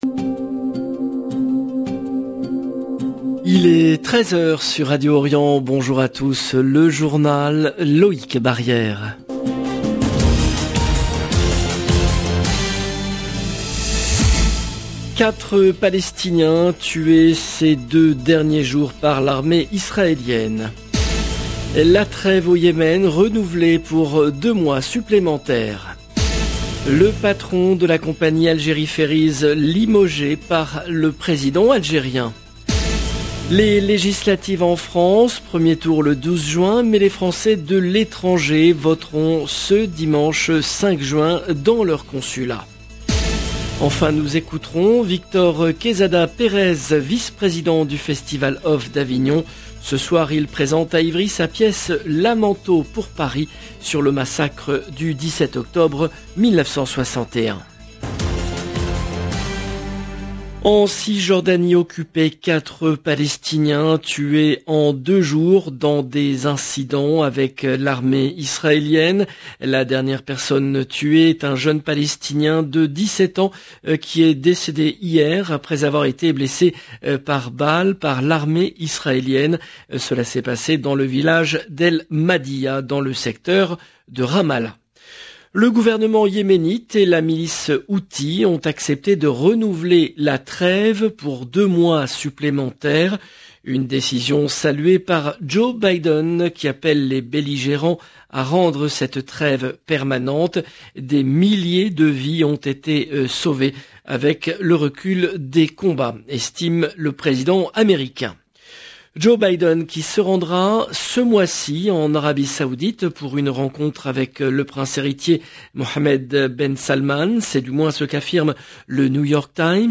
LE JOURNAL EN LANGUE FRANCAISE DE LA MI-JOURNEE DU 3/06/22